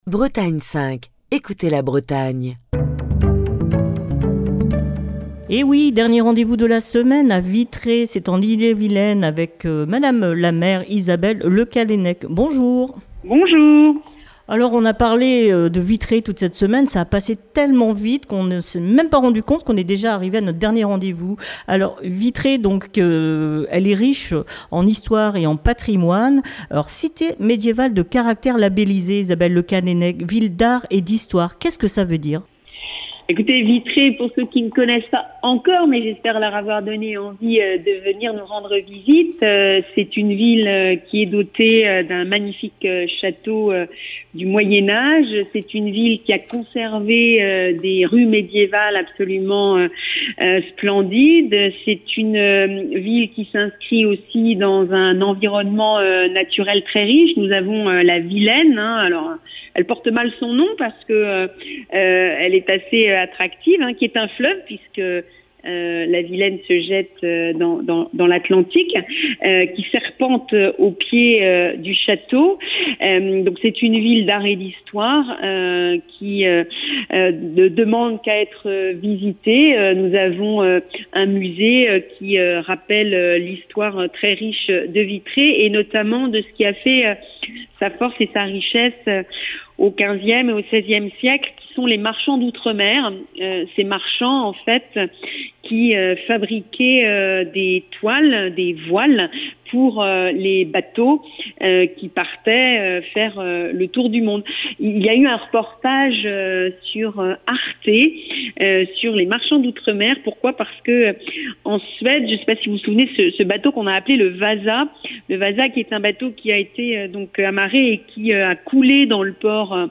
au téléphone avec la maire de Vitré, Isabelle Le Callennec